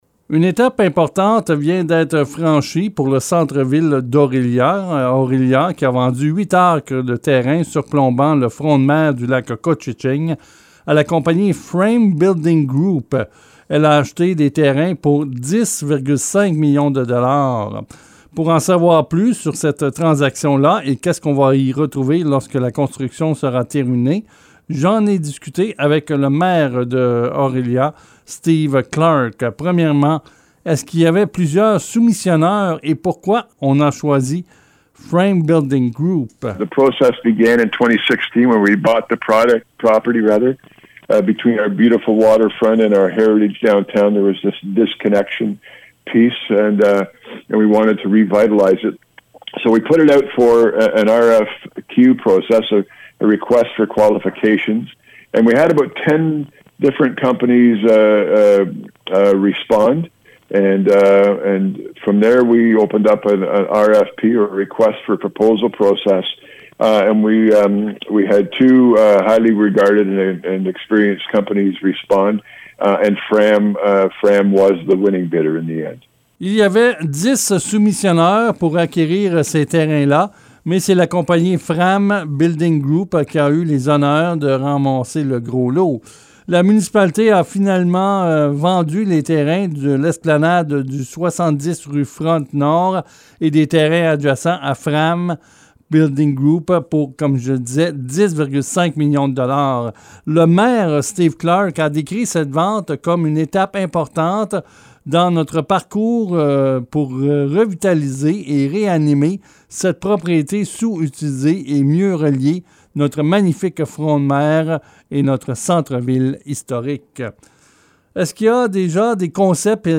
Entretien avec le maire d'Orillia Steve Clarke, au sujet de la vente de terrain au bord de l’eau à FRAM Building Group :